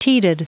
Prononciation du mot teated en anglais (fichier audio)
Prononciation du mot : teated